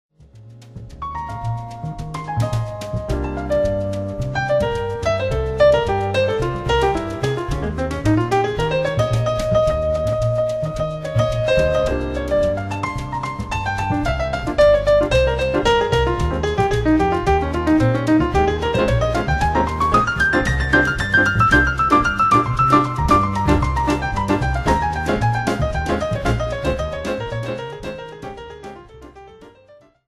將古典大師的作品改編成爵士三重奏的型式，讓高雅的 古典曲目增添了輕快寫意的風味